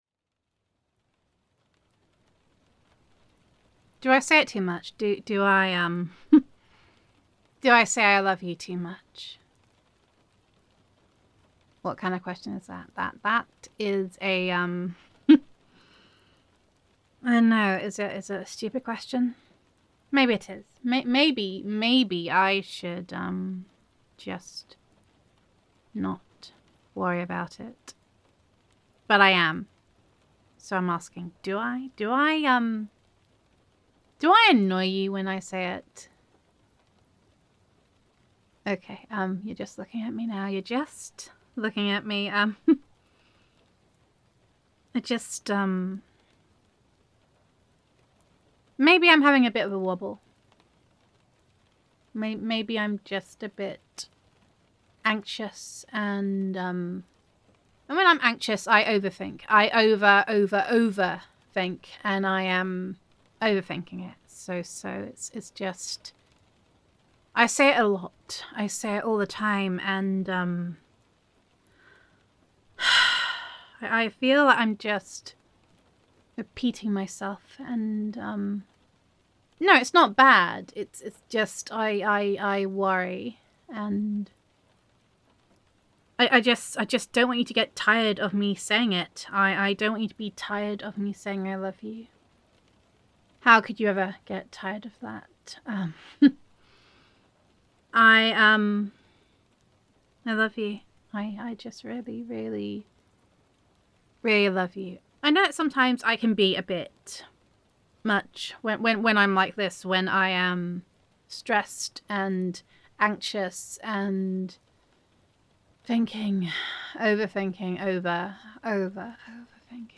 [F4A] Do I Say It Too Much? [Girlfriend Roleplay][Reverse Comfort][Adoration][Appreciation][Teary in Places][Heartfelt][Hold Me][Overthinking][Vulnerability][Gender Neutral][Comforting Your Girlfriend in an Anxious Moment After a Rough Day]